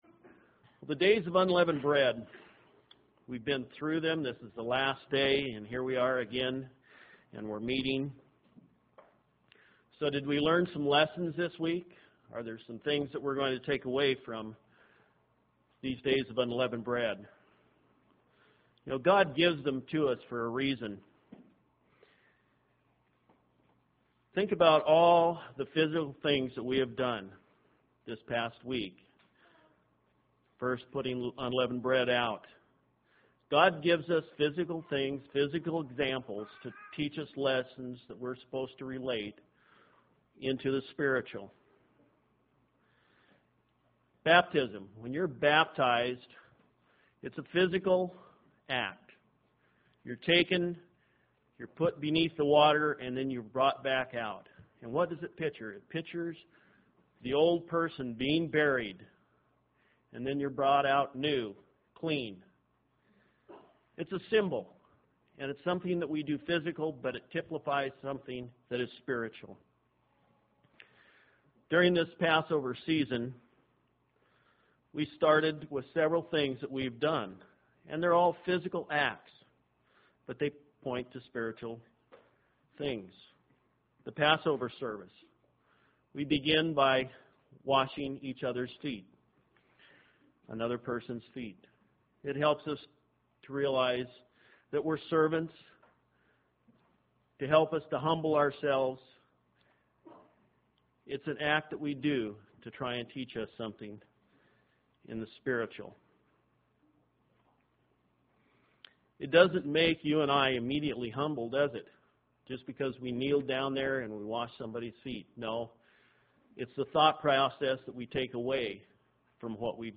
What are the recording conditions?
Given in Spokane, WA